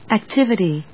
/æktívəṭi(米国英語), æˈktɪvɪti:(英国英語)/
フリガナアックティビティー